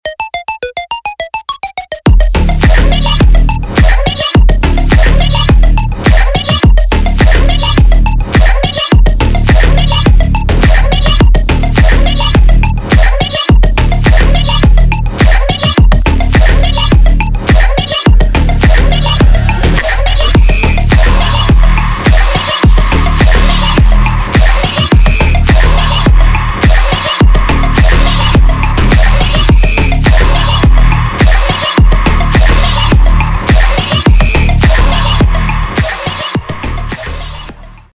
• Качество: 320, Stereo
громкие
жесткие
мощные
Electronic
Bass
Стиль: techno